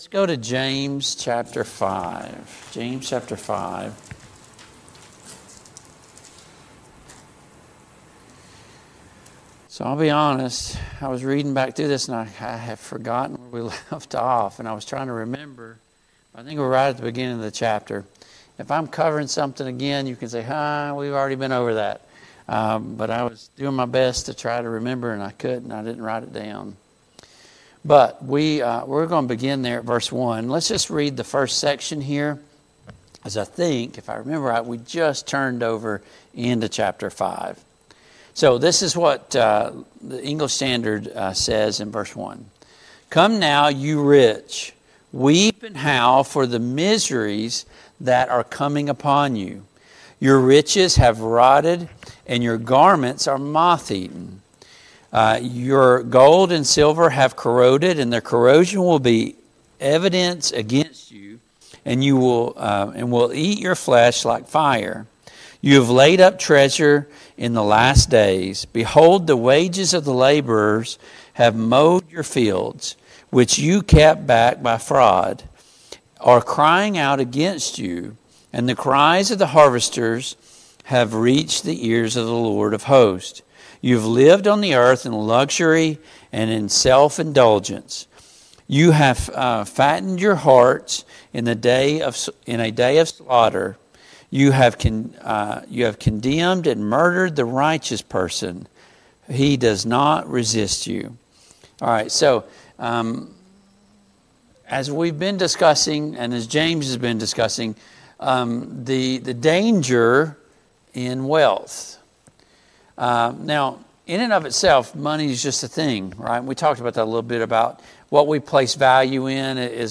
Service Type: Family Bible Hour Topics: Contentment , Greed , Riches